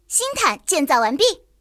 野牛建造完成提醒语音.OGG